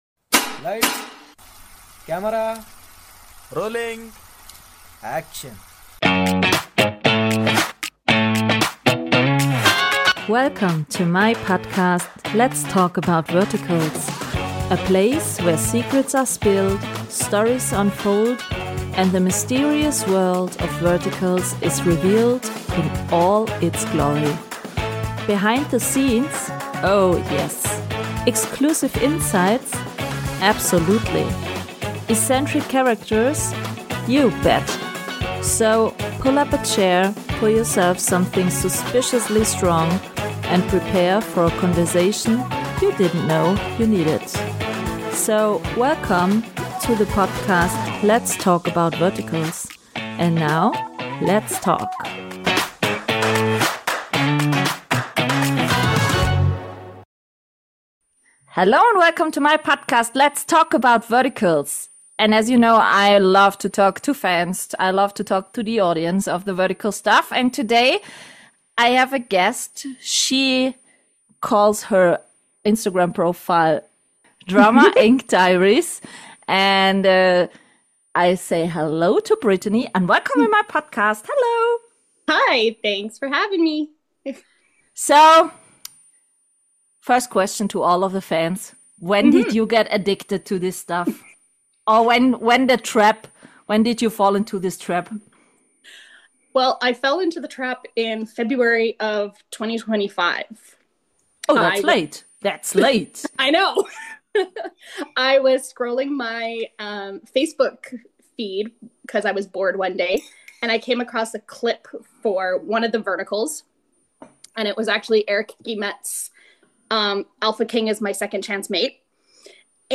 The wish list is long , the conversation flows effortlessly, and the vibes are just right .